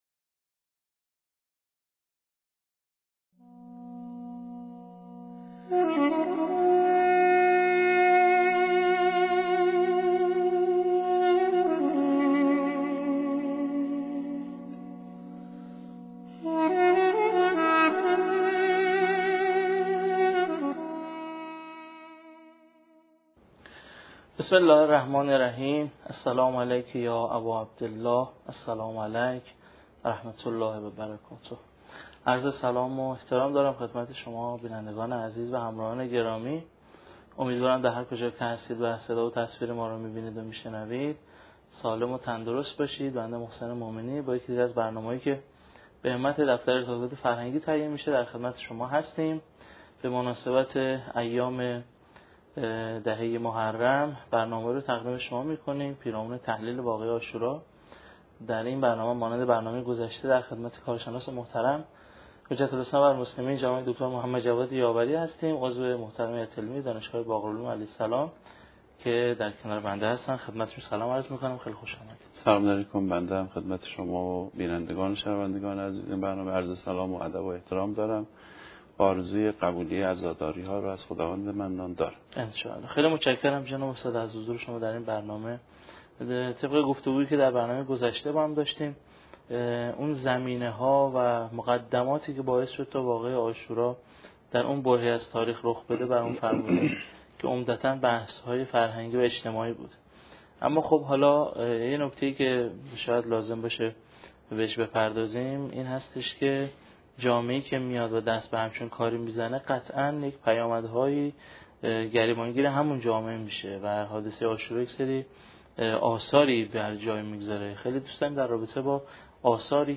این گفت‌وگو به همت دفتر آزاد فرهنگی تهیه شده و در شبکه‌های اجتماعی و سایت این دفتر منتشر می‌شود. 2. آثار کوتاه‌مدت قیام عاشورا قیام امام حسین (ع) باعث احیای روحیه مبارزه با ظلم شد.